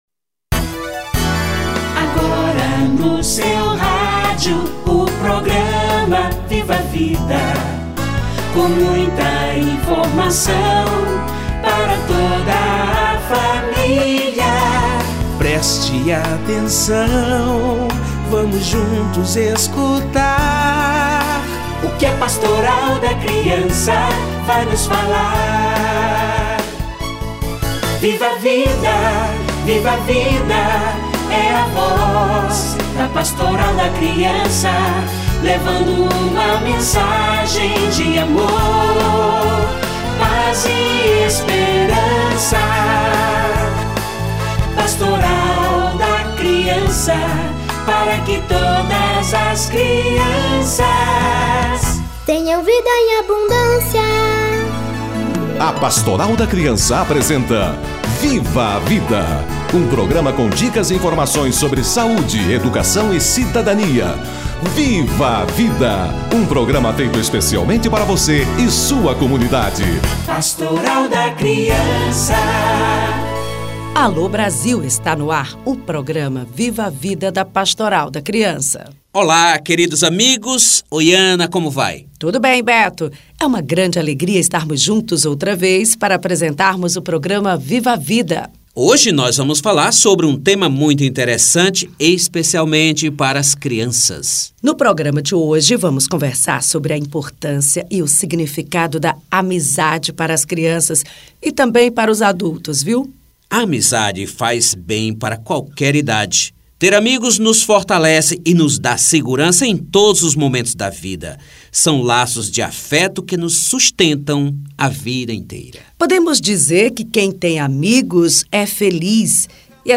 Amizades na Infância - Entrevista